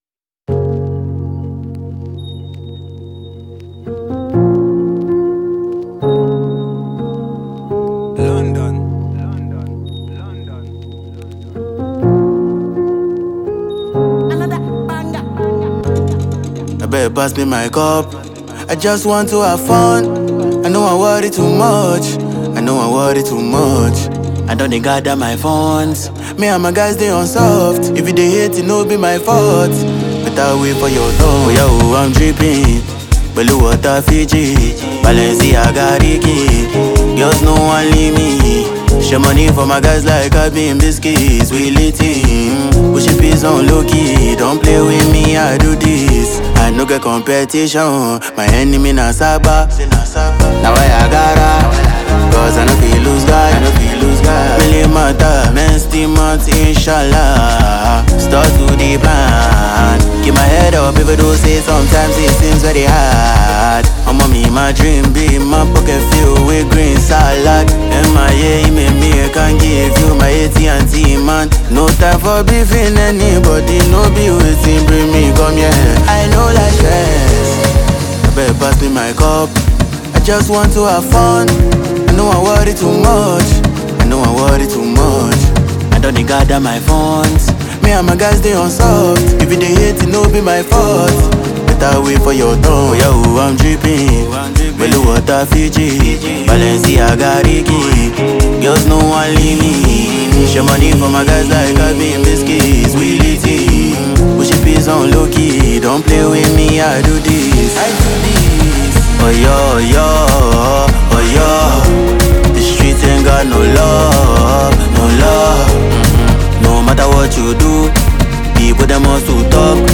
Afrobeats
afrobeat rhythms, amapiano grooves